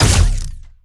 Added more sound effects.
GUNAuto_Plasmid Machinegun Single_01_SFRMS_SCIWPNS.wav